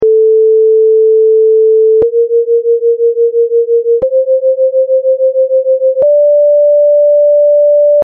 tetracordo diatonico diatono + incitato (sovrapposti)